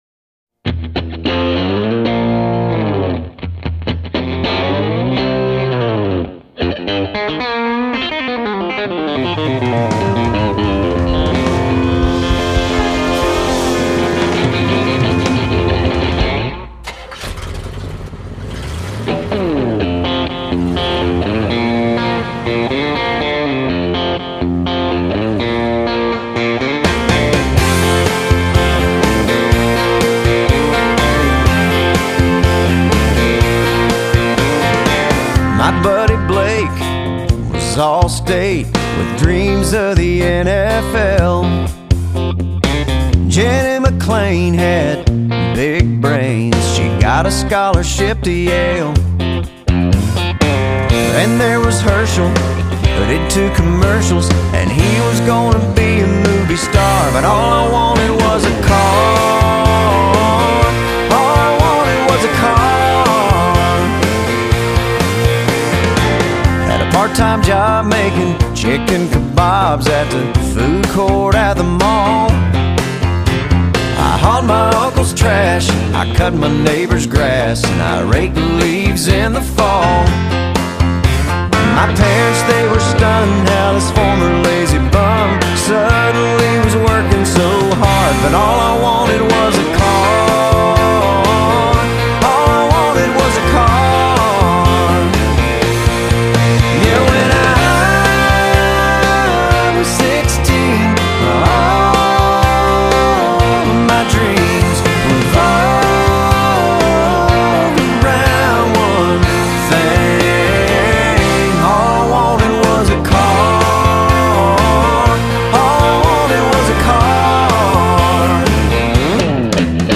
音乐风格：Neo-Traditionalist Country（新传统主义者乡村），
Contemporary Country（当代乡村）